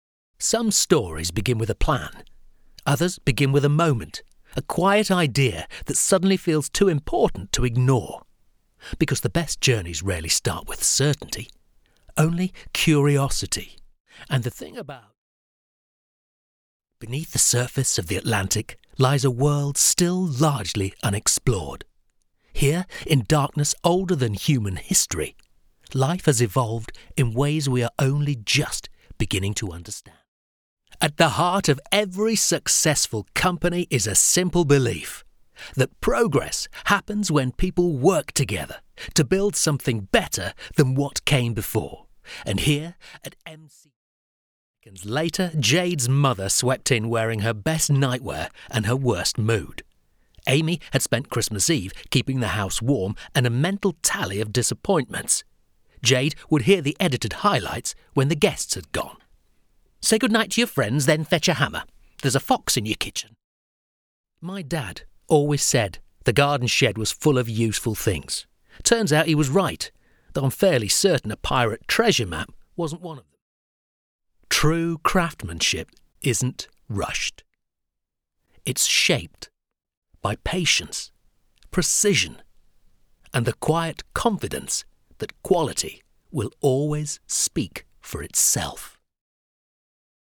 British voiceover demo reel
Broadcast voice with 20+ years presenting breakfast radio.
Home studio • Audiobook narration • Commercial storytelling